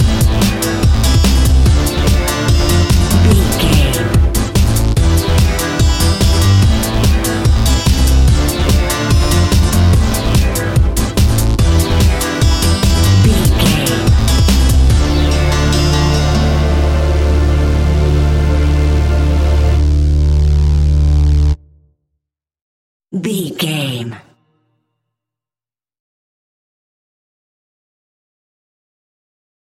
Aeolian/Minor
Fast
driving
energetic
hypnotic
drum machine
synthesiser
acid house
electronic
uptempo
synth leads
synth bass